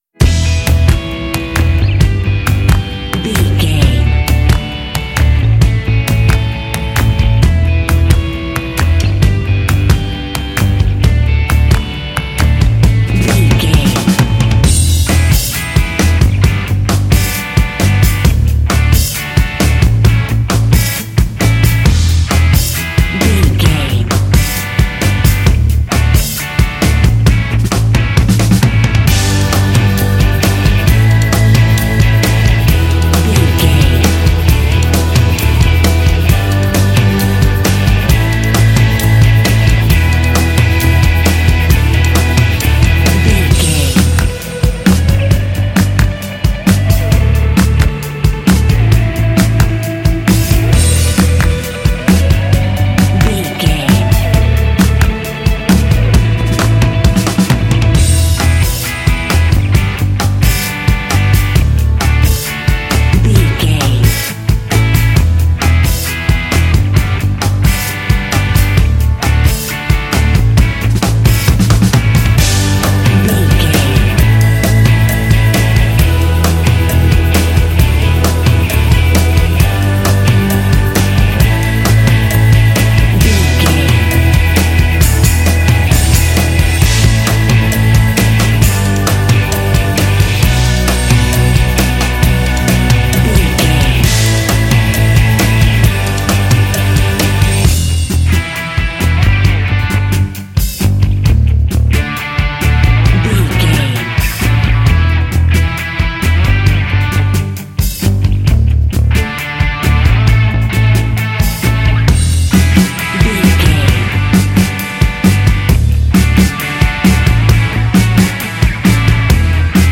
This rock/pop track is ideal for kids games and board games.
Ionian/Major
fun
energetic
bright
lively
sweet
electric guitar
drums
bass guitar
electric organ
rock